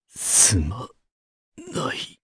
Clause_ice-Vox_Dead_jp.wav